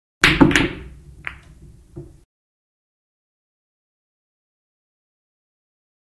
На этой странице собраны звуки бильярда: от четких ударов кием до глухого стука шаров и их падения в лузу.
Звук удара шара о бильярдный шар